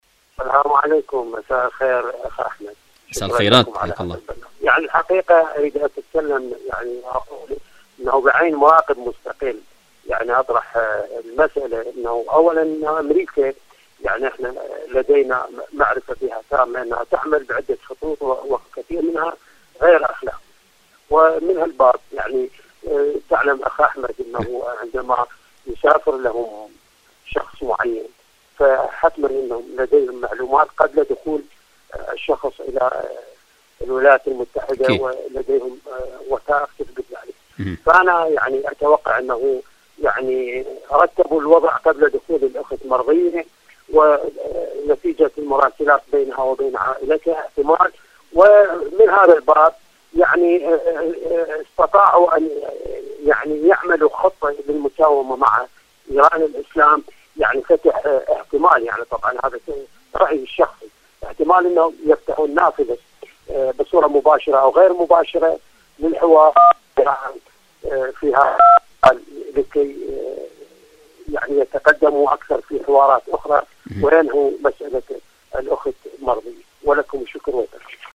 برنامج : ألو طهران / مشاركة هاتفية